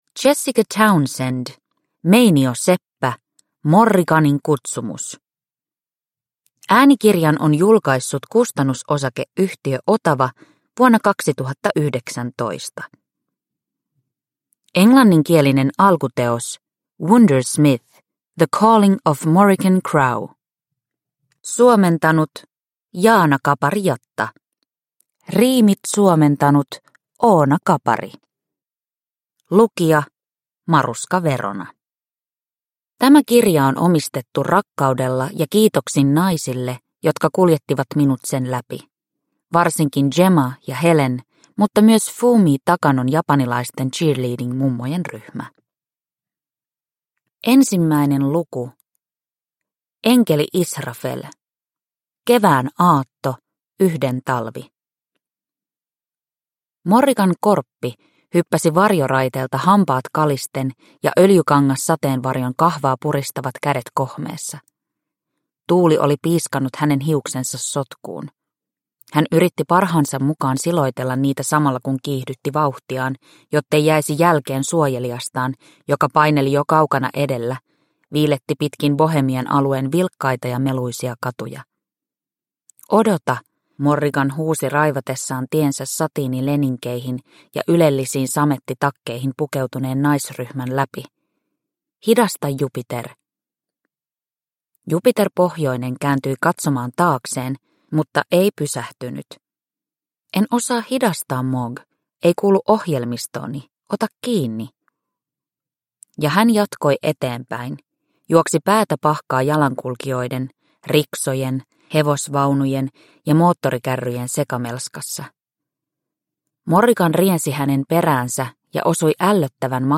Meinioseppä - Morriganin kutsumus – Ljudbok – Laddas ner